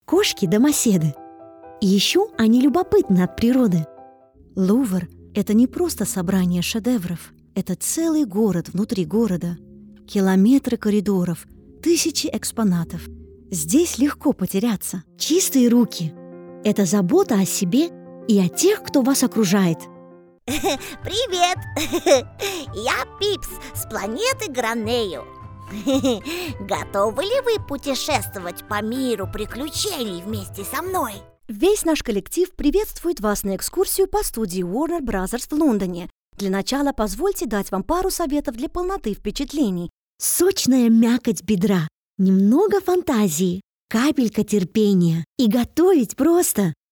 Demonstração Comercial
Cabine tratada
Microfone Rode NT1a
Mezzo-soprano